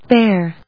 /béɚ(米国英語), béə(英国英語)/